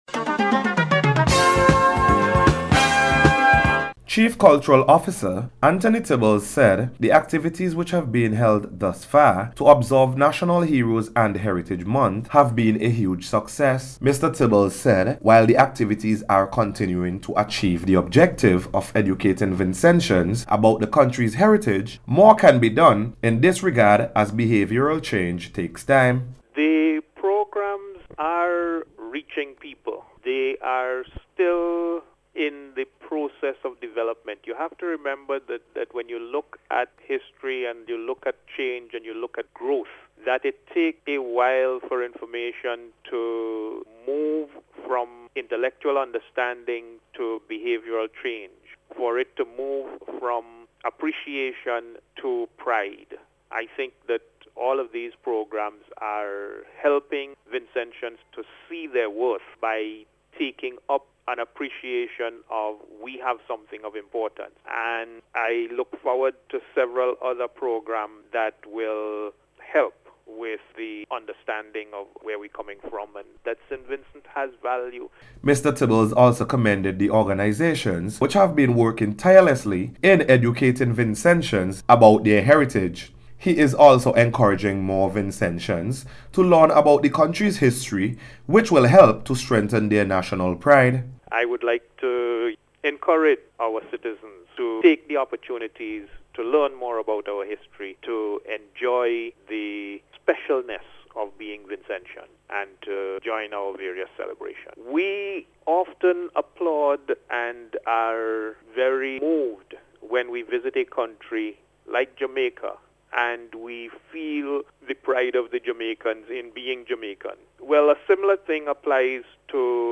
Meanwhile a number of Vincentians have voiced their opinions on the issue of Culture and Heritage.
HEROES-AND-VINCENTIANS-REPORT.mp3